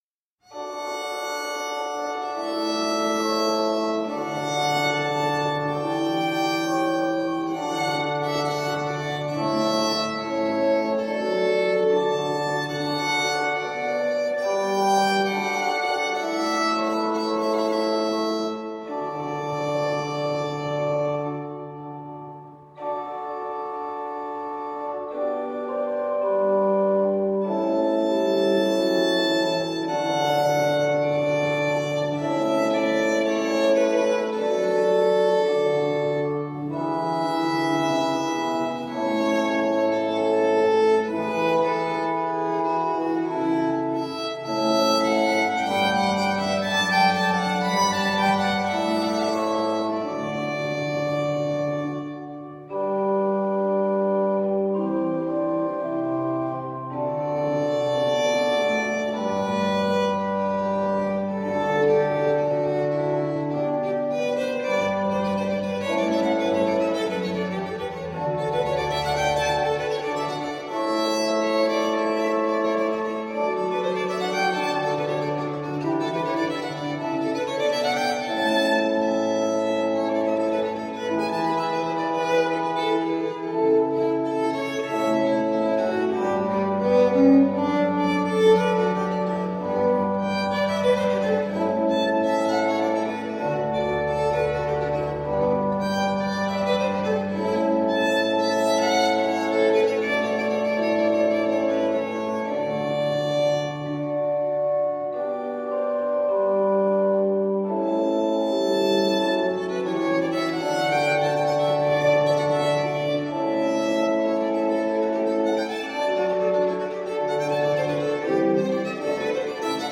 17th century baroque ensemble.
Classical, Orchestral, Baroque, Instrumental
Organ, Violin